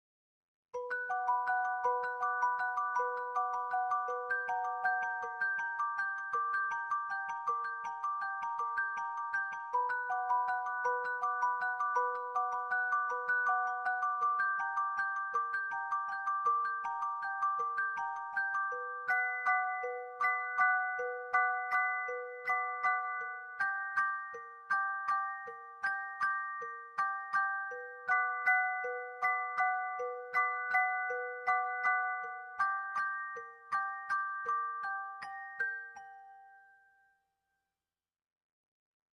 这是一款玻璃铃声钢琴音色，可以自由演奏旋律，还自带了PAD铺底音色
梦幻空灵钢琴 音色界面
音色展示
钢琴
我就喜欢这种神秘的声音